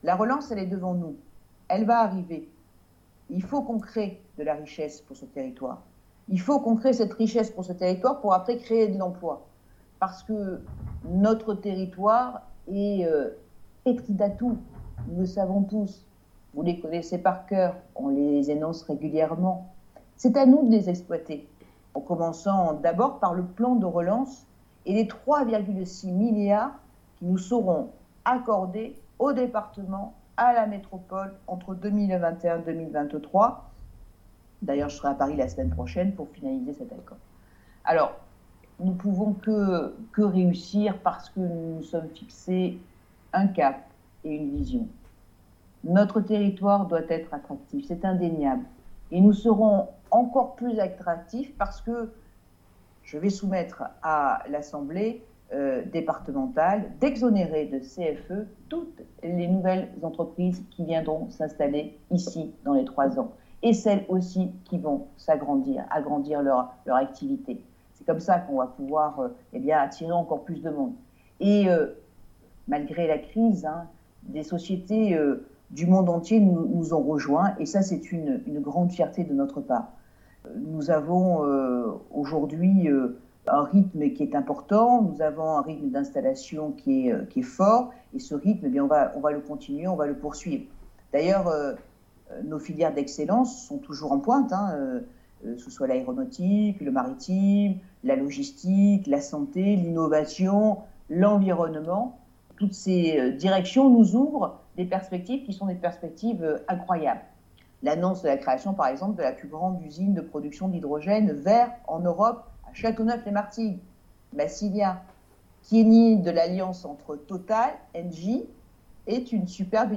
Vœux à la presse de Martine Vassal : -Nous avons réalisé des promesses des années 70-